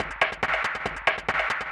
K-1 Perc 1.wav